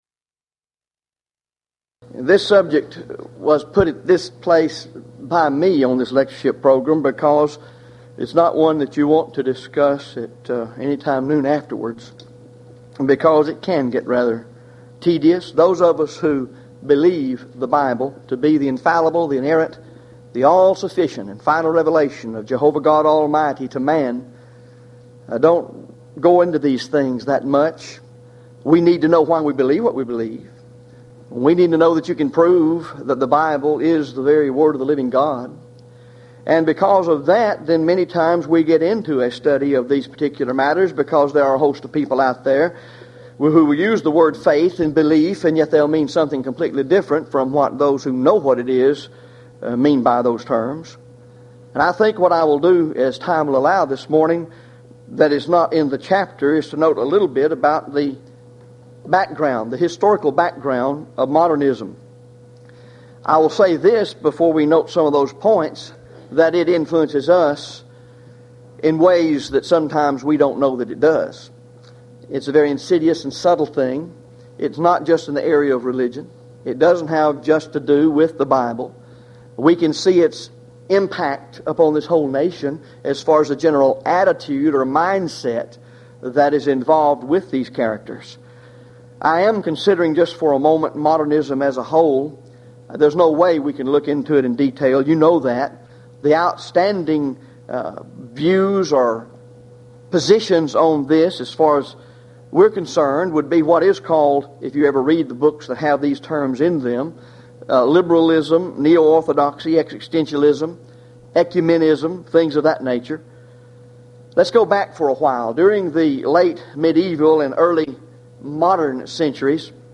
Houston College of the Bible Lectures